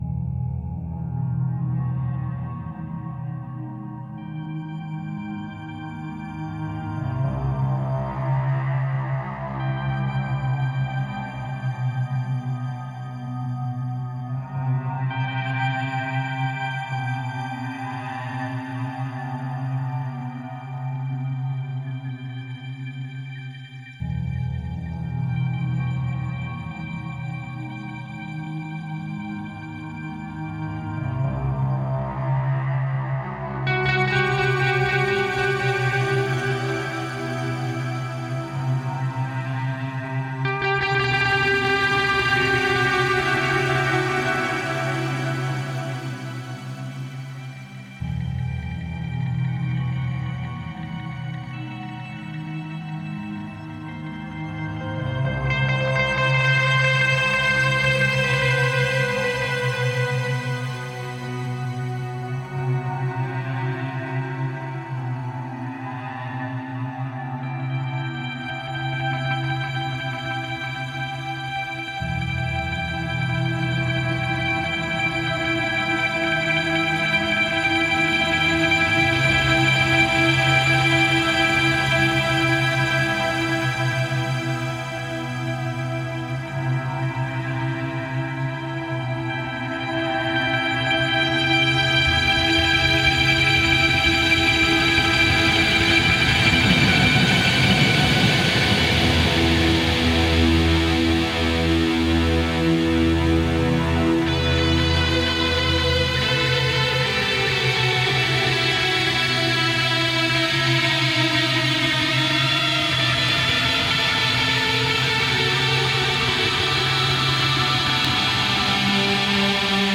Genre: techno, rock.